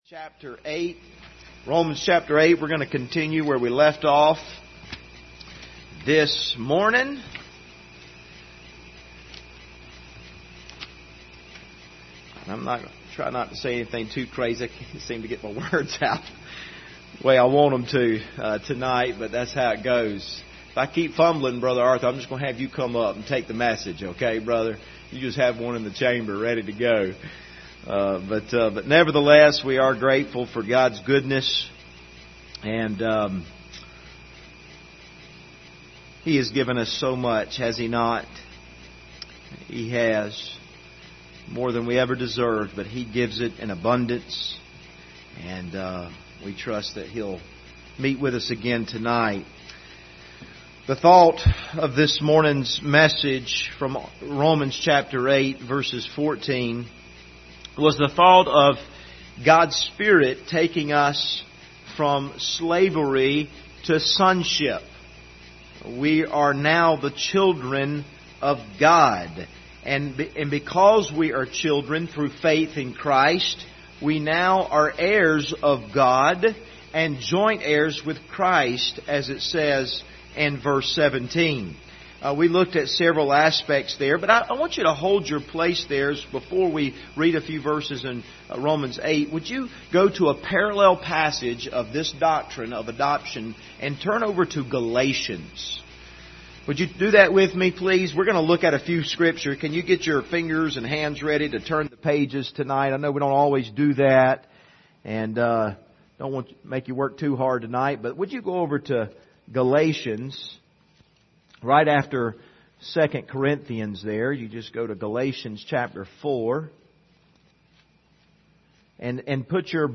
Passage: Romans 8:14-17 Service Type: Sunday Evening